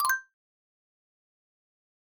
tweet_send.ogg